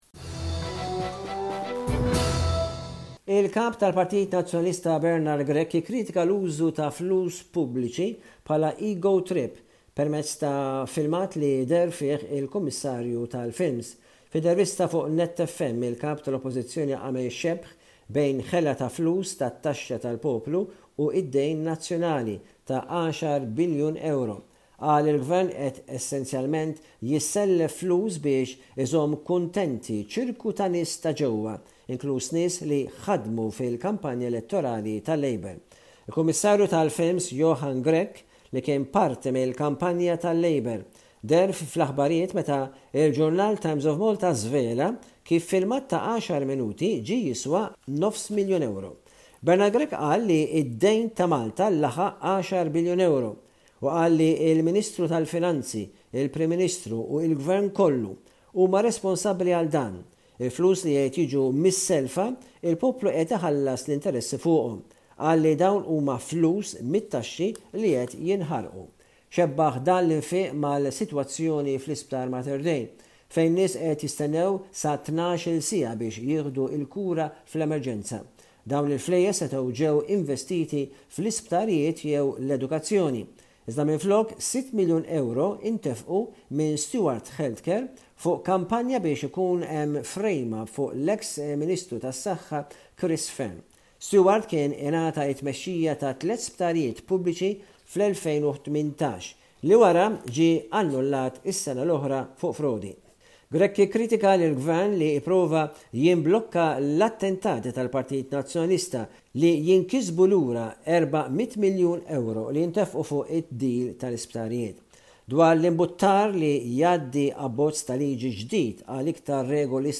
Bullettin ta' aħbarijiet minn Malta